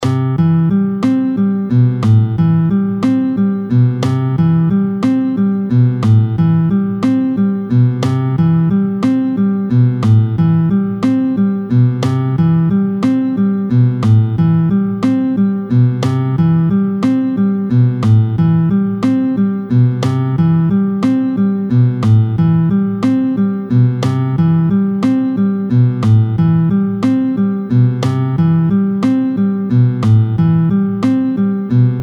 tempo 60